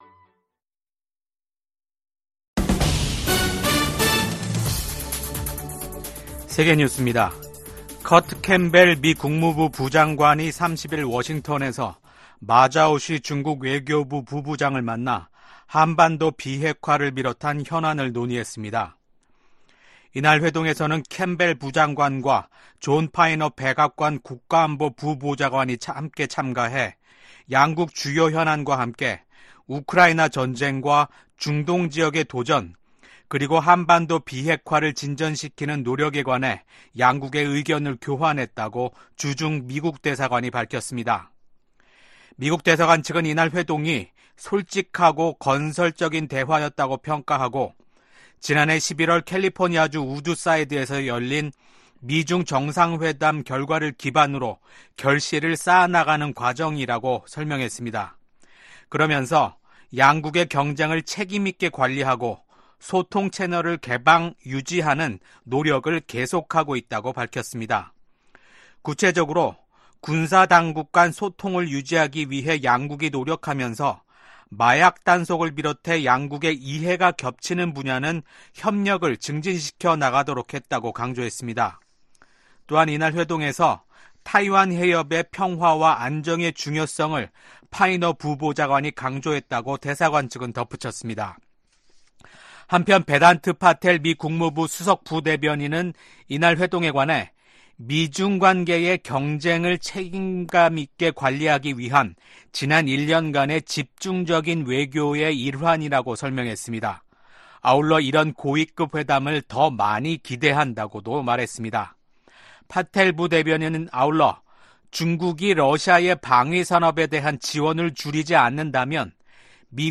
VOA 한국어 아침 뉴스 프로그램 '워싱턴 뉴스 광장' 2024년 6월 1일 방송입니다. 미국 정부는 한반도에 핵무기를 재배치할 계획이 없다고 국무부 대변인이 밝혔습니다.